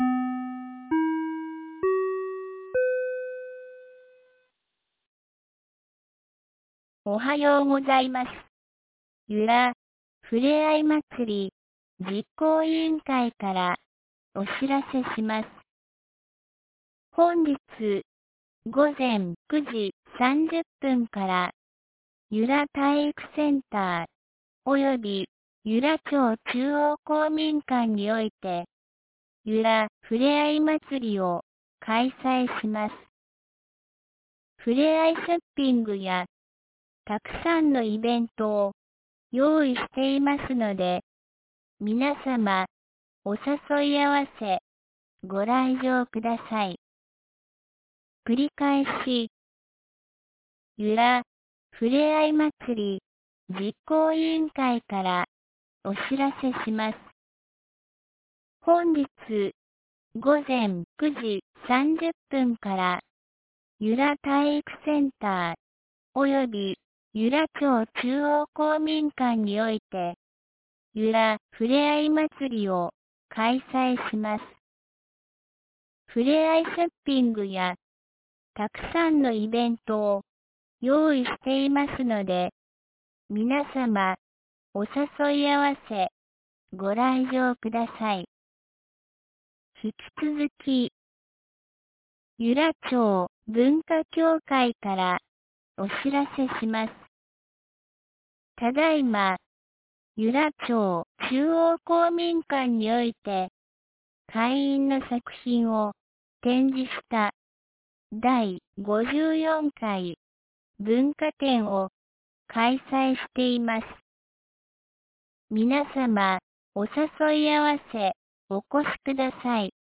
2019年11月03日 07時53分に、由良町から全地区へ放送がありました。